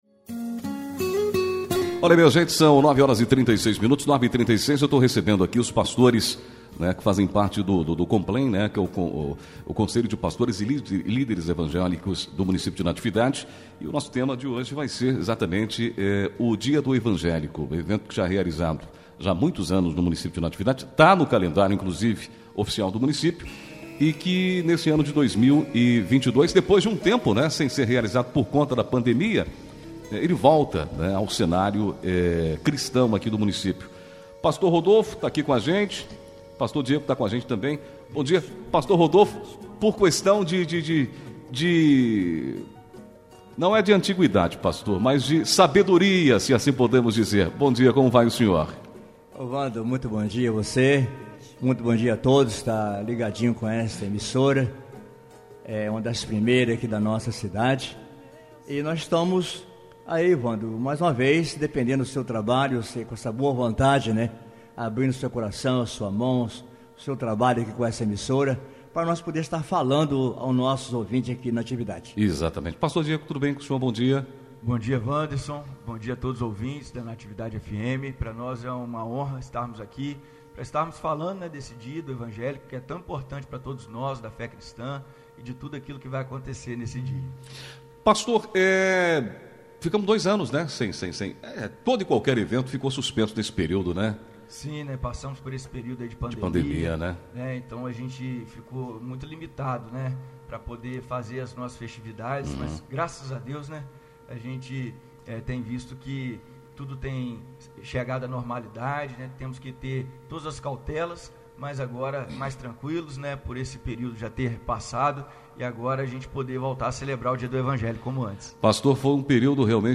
26 agosto, 2022 DESTAQUE, ENTREVISTAS